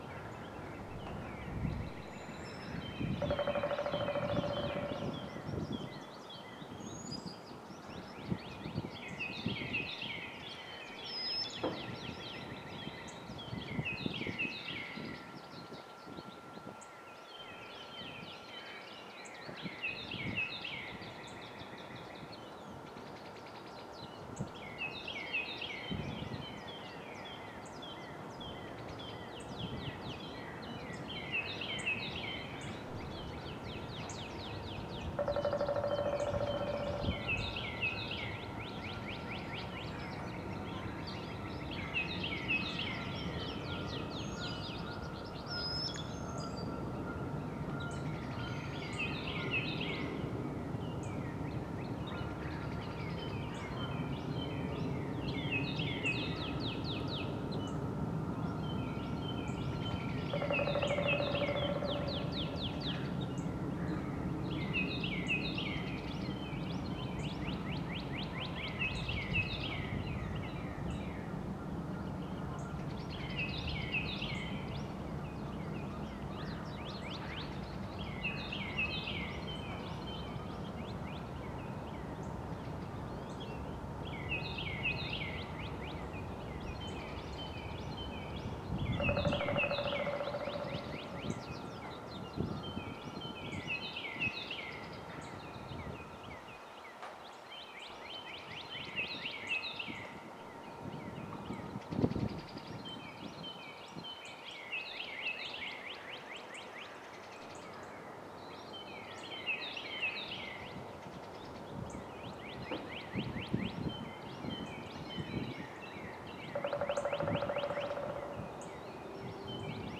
Evening Rain along the Wild Pacific Trail (Field Recording 17)
pan-field.wav